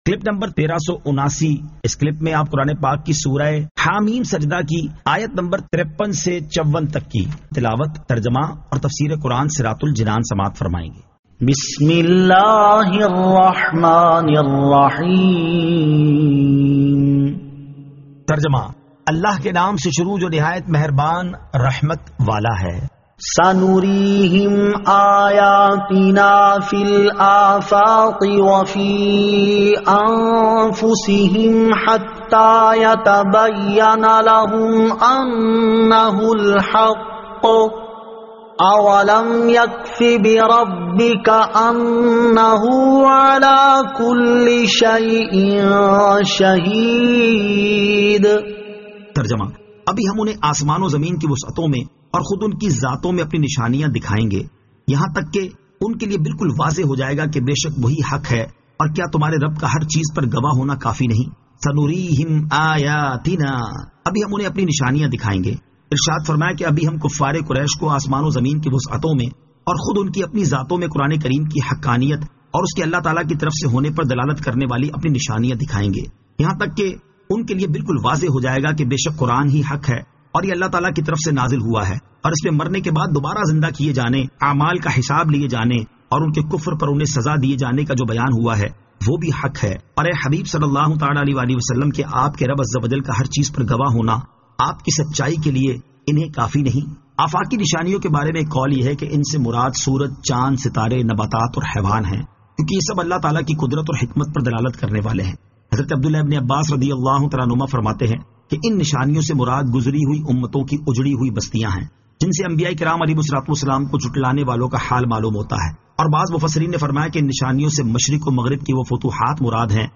Surah Ha-Meem As-Sajdah 53 To 54 Tilawat , Tarjama , Tafseer
2023 MP3 MP4 MP4 Share سُوَّرۃُ حٰمٓ السَّجْدَۃِ آیت 53 تا 54 تلاوت ، ترجمہ ، تفسیر ۔